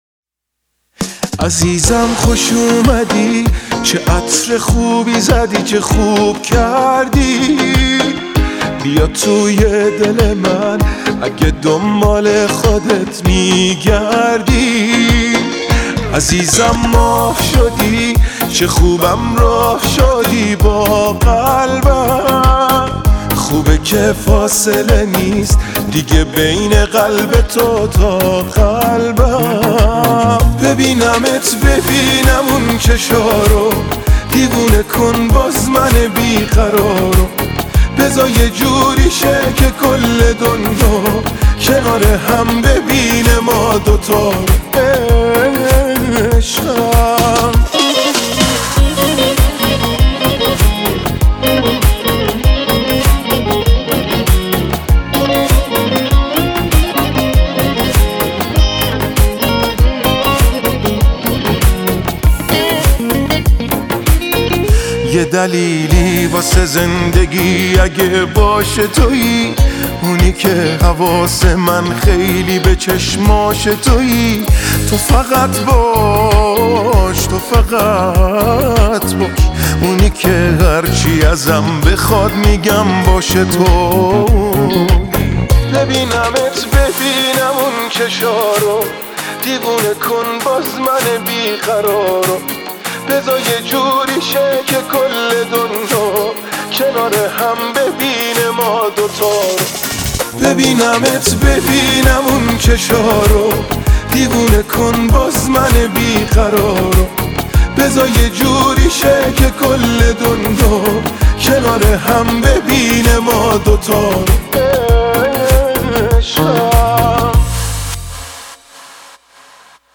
موسیقی پاپ
آهنگ جوان پسند